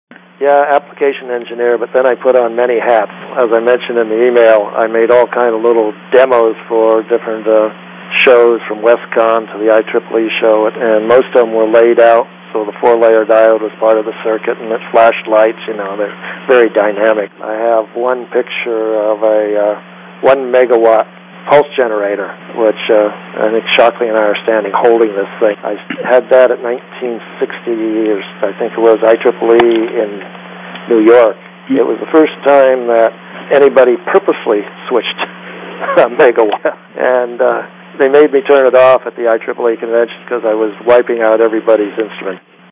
These Audio Links are Excerpts from the August 2003 Interview